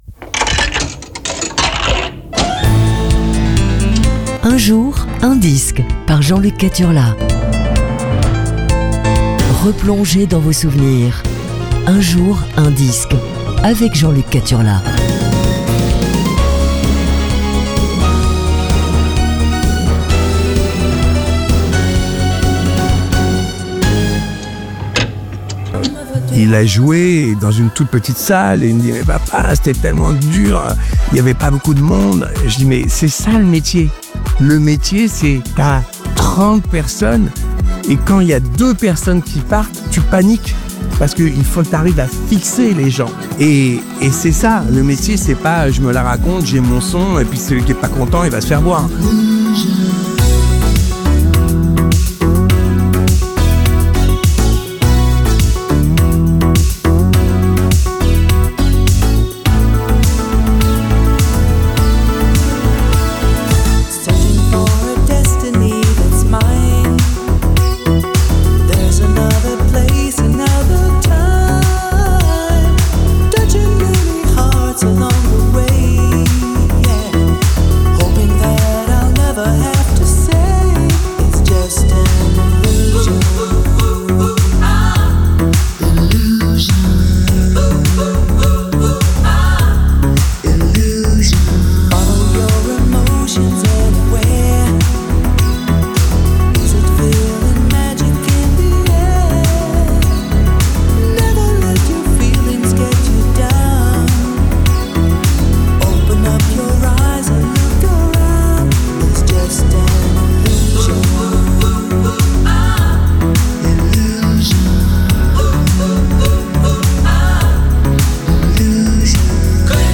Aujourd'hui c'est Disco Funk - 2